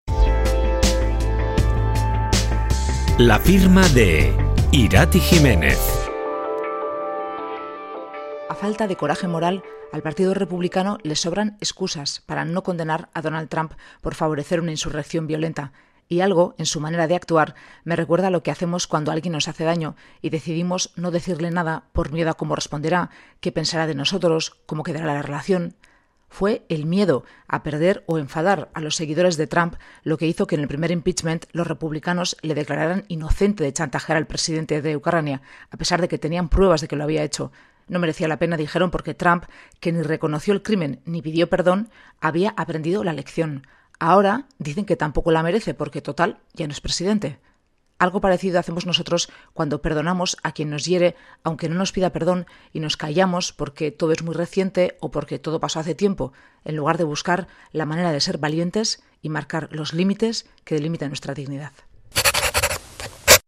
Columna radiofónica de opinión